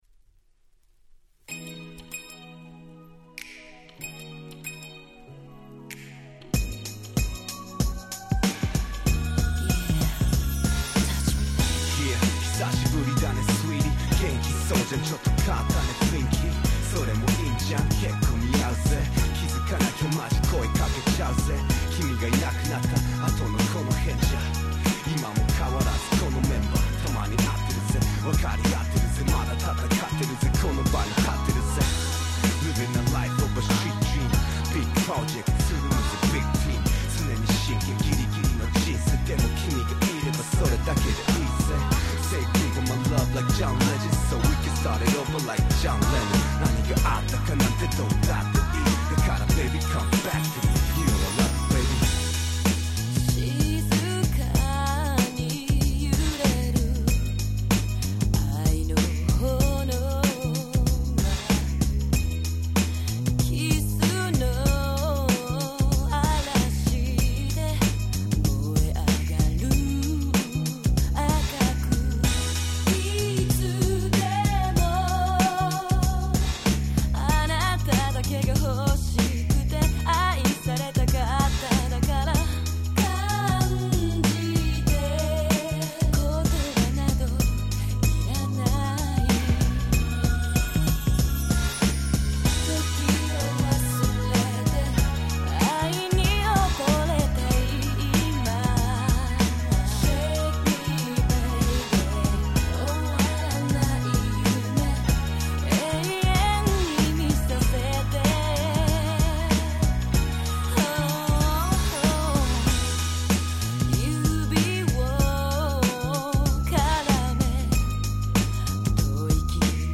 98' Nice Japanese R&B !!